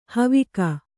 ♪ havika